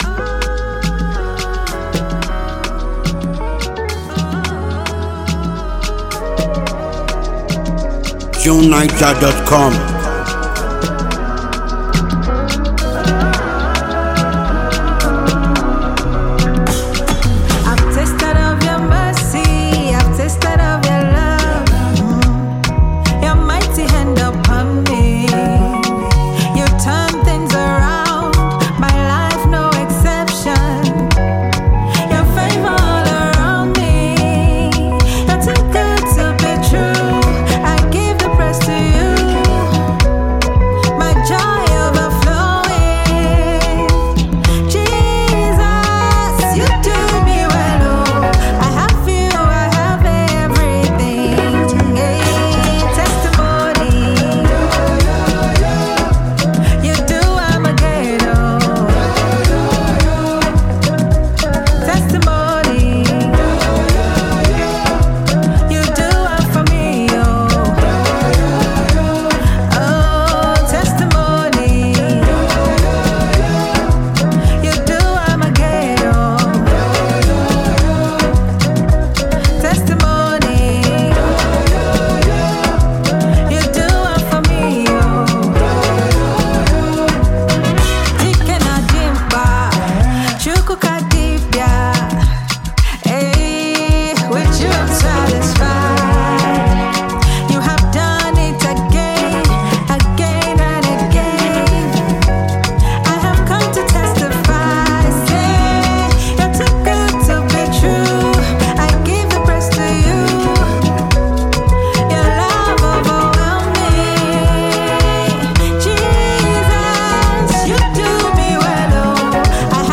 Nigerian gospel singer and songwriter